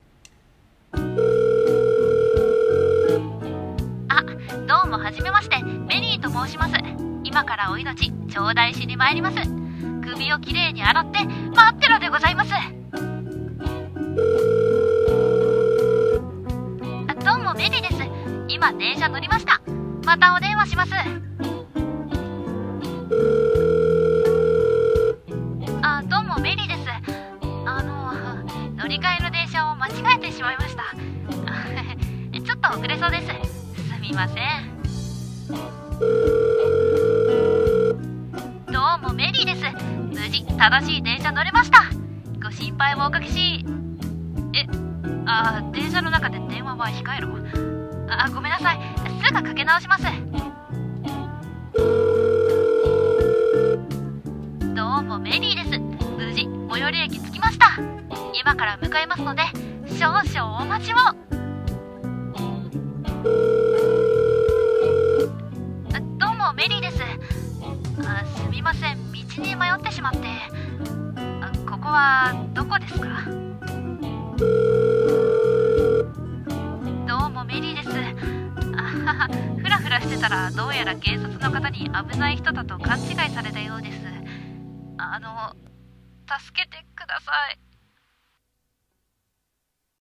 ギャグ声劇台本】どうも、メリーです。